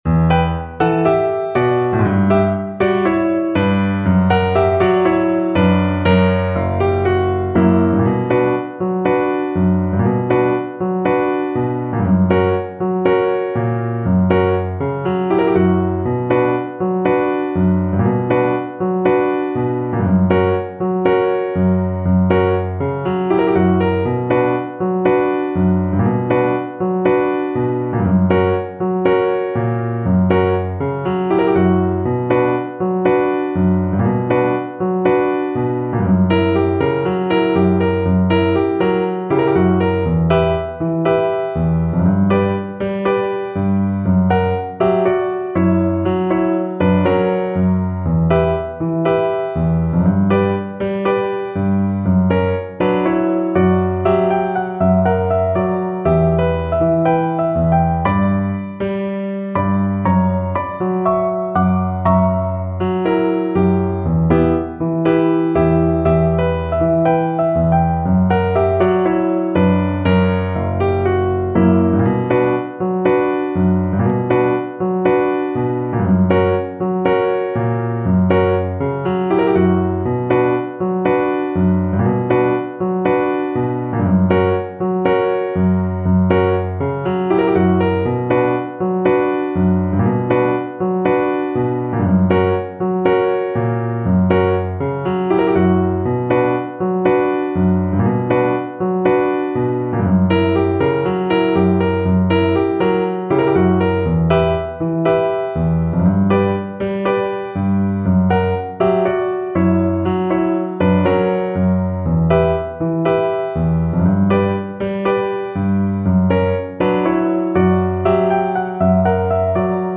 Violin version
D major (Sounding Pitch) (View more D major Music for Violin )
Slow two in a bar =c.60
Traditional (View more Traditional Violin Music)